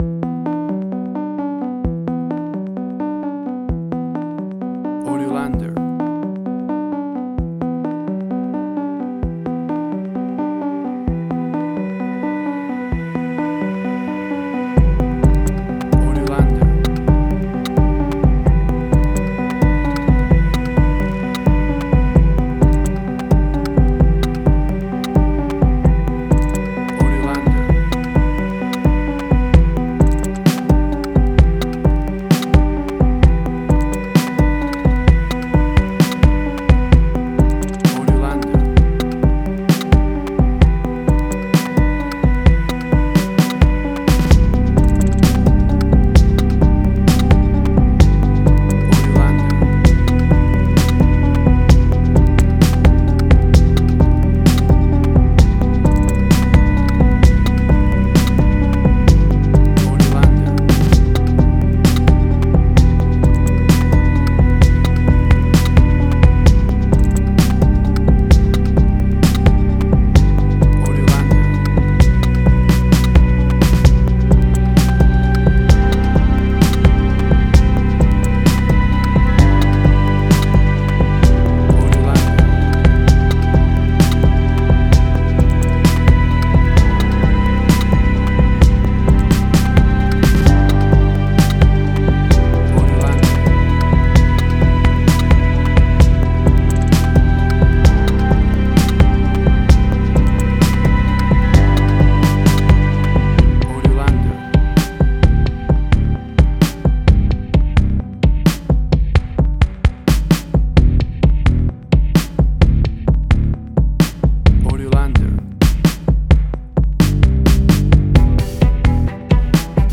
IDM, Glitch.
Tempo (BPM): 65